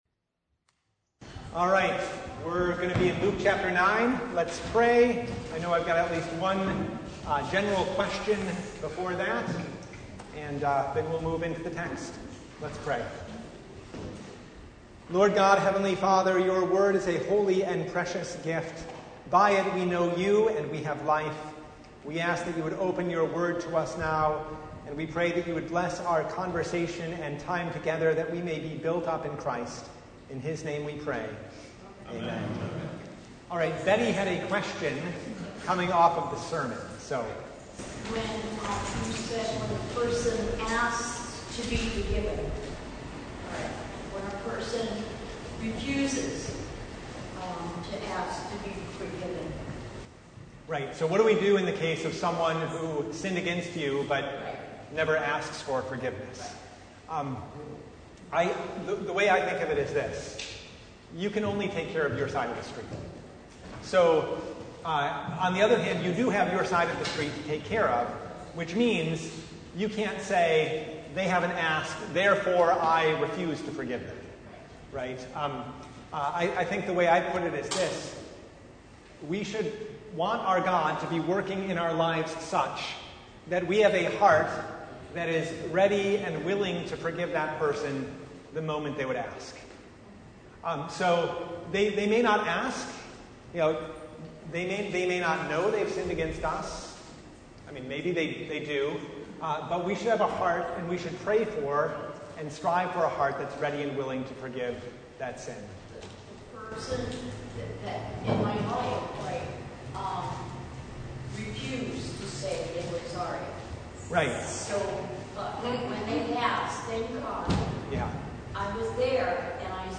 Service Type: Bible Study